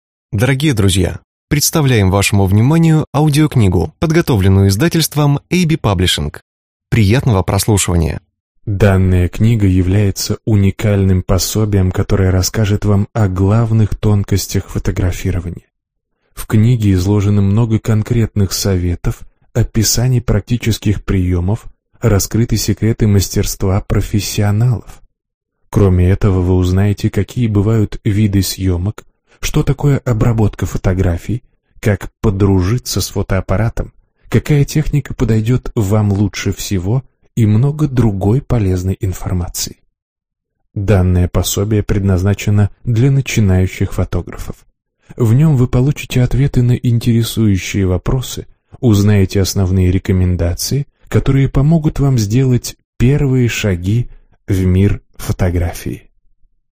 Аудиокнига Учимся фотографировать. Практические советы | Библиотека аудиокниг